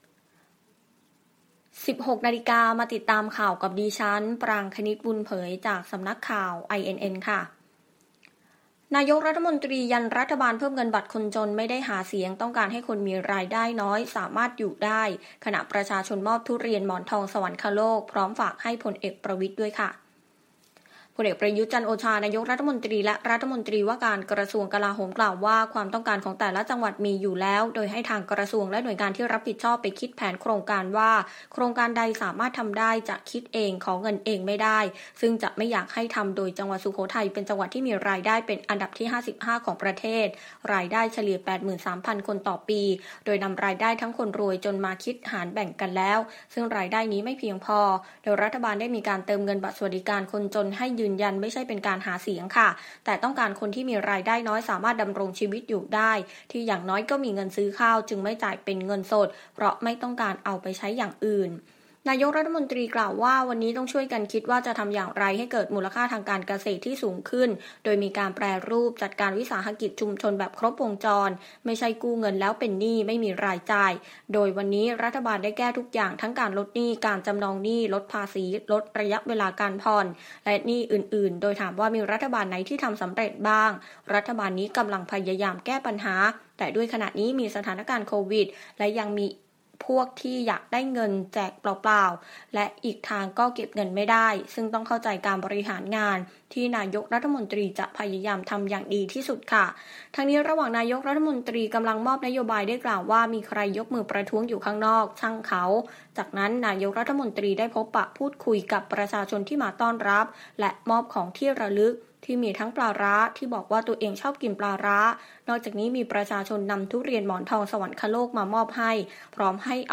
Video คลิปข่าวต้นชั่วโมง ข่าว